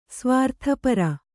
♪ svārtha para